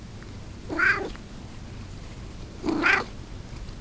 Buzzy's Meow (Sound)
When Buzzy leaps into my lap, he meows a lot at first - as though begging me to pet him.
I don't know if the recorder picked up the purring, too.
Buzzy Meows (wav)
BuzzyMeow.wav